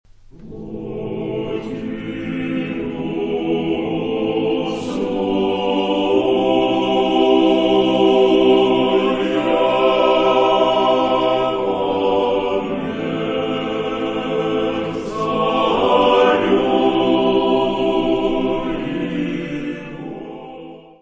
Descrição Física:  1 Disco (CD) (55 min.) : stereo; 12 cm
Área:  Música Clássica